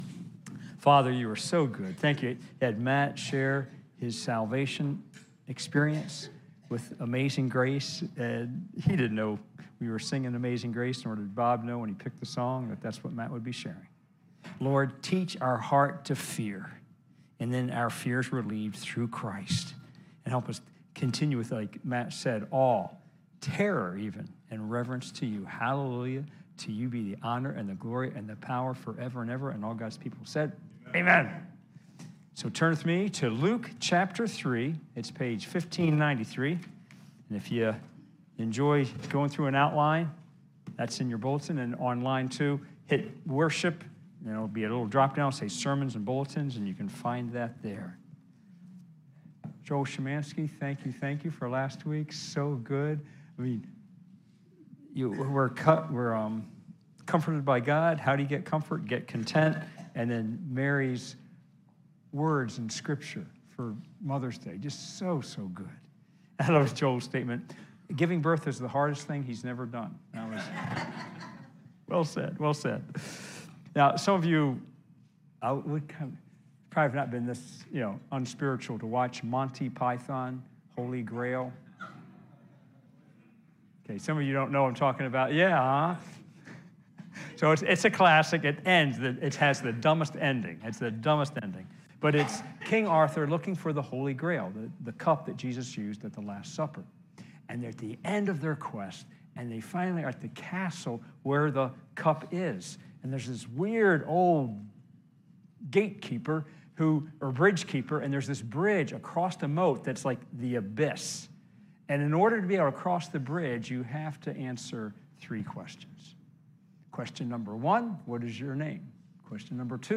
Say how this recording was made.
Luke 3 – Your Kingdom Come, Your Will Be Done Replay: May 15, 2022 live stream worship service.